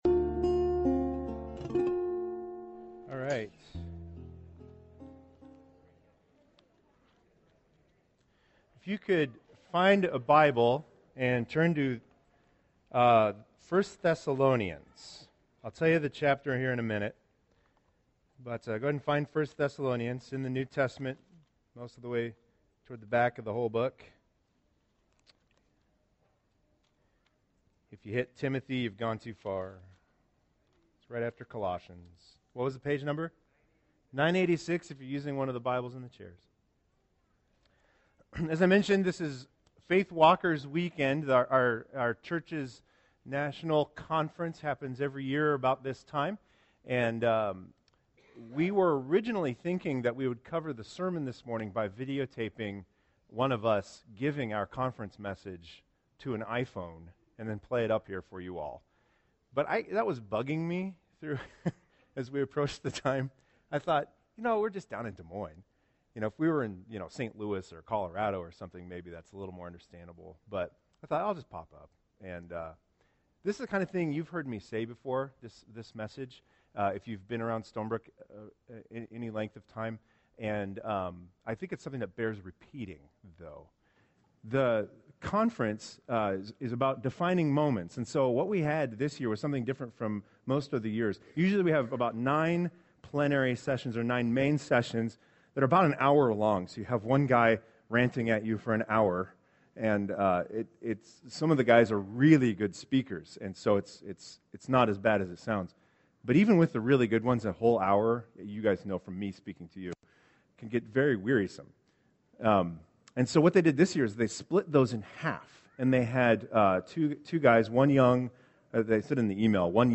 2016 Stay up to date with “ Stonebrook Church Sermons Podcast ”